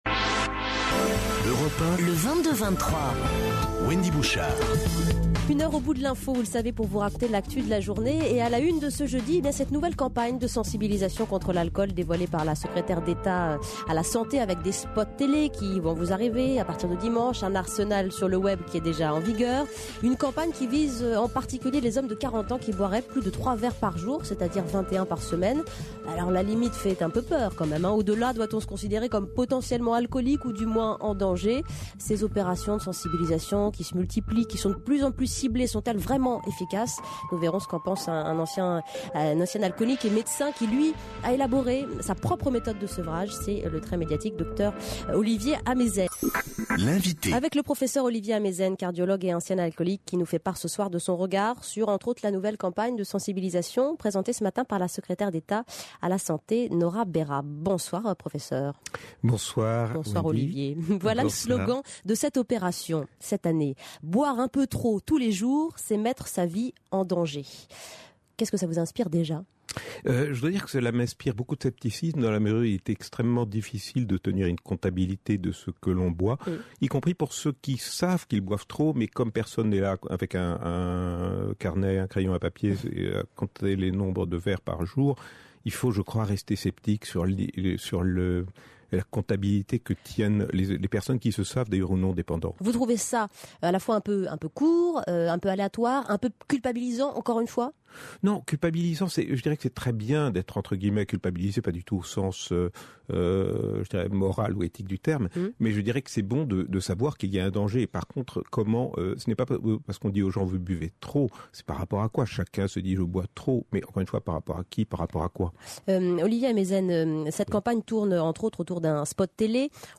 Baclofène – Interview d’Olivier Ameisen